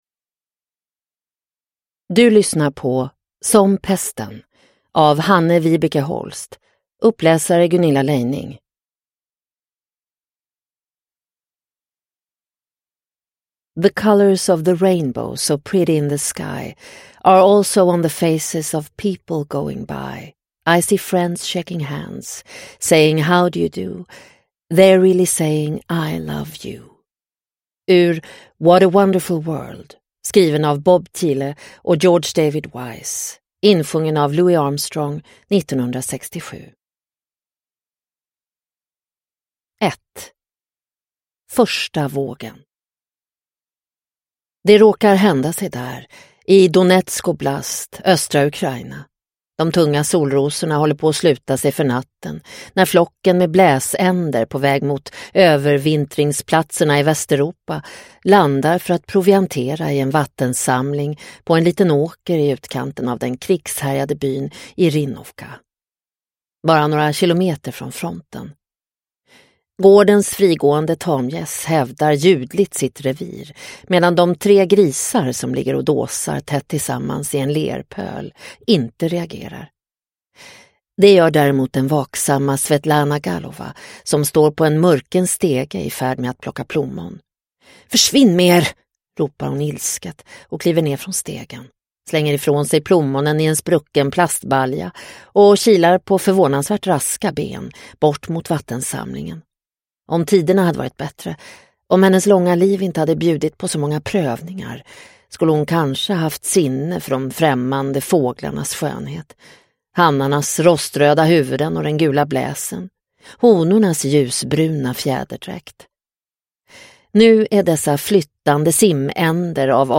Som pesten – Ljudbok – Laddas ner